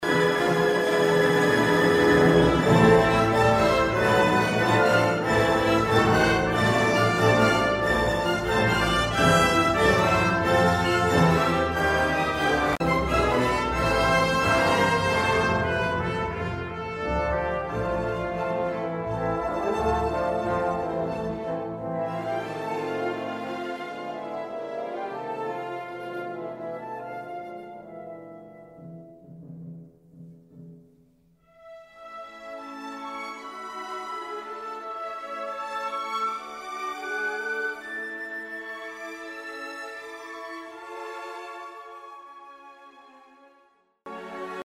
A good example is the way in which the first and second subjects appear simultaneously in the recapitulation before giving way to a reprise of a development theme, but this time in A major.
Example 3 – Themes combined in recapitulation: